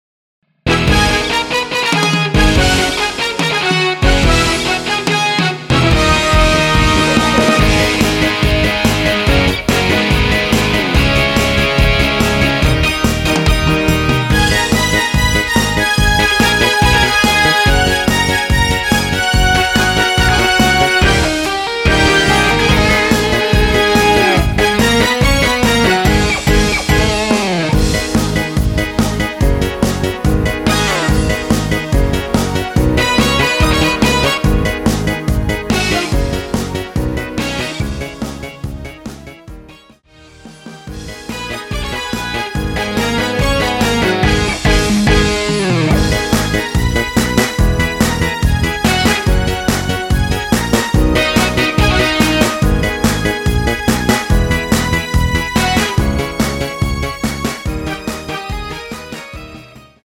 여성분이 부르실 수 있는 키의 MR입니다.(미리듣기 확인)
원키에서(+4)올린 MR 입니다.
G#m
앞부분30초, 뒷부분30초씩 편집해서 올려 드리고 있습니다.